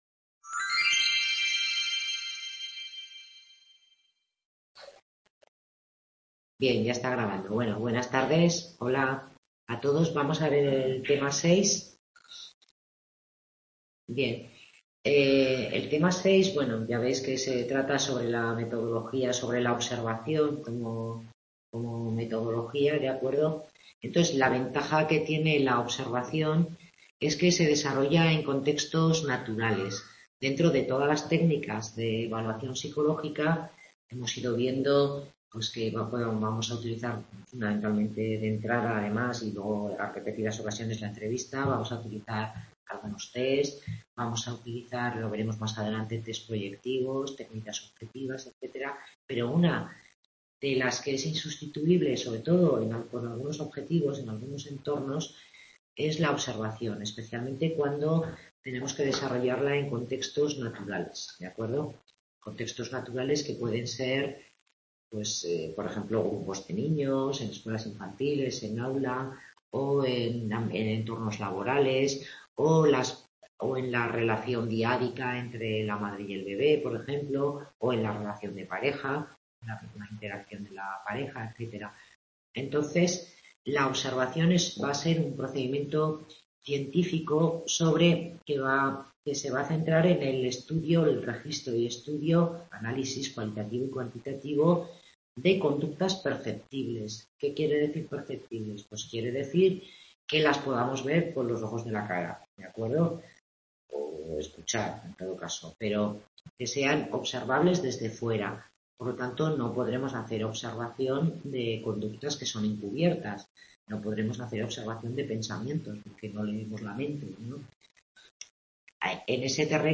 Tutoría grupal sobre técnicas de observación en evaluación psicológica